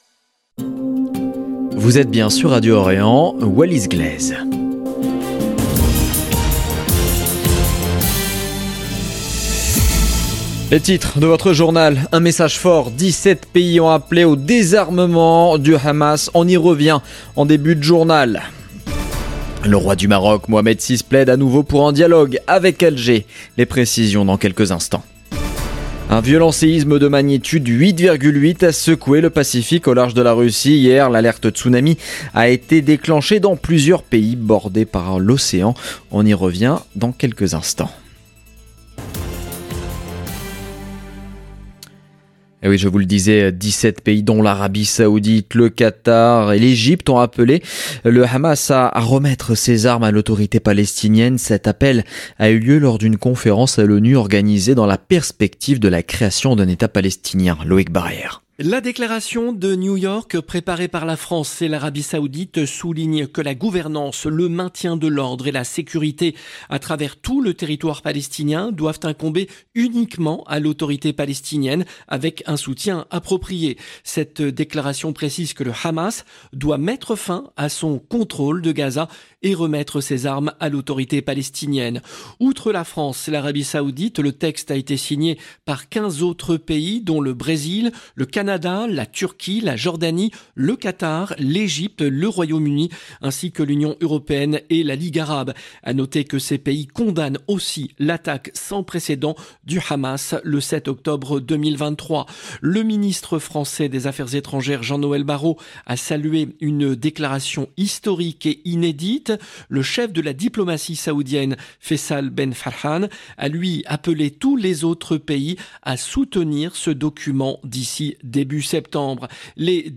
Journal de midi du 30 juillet 2025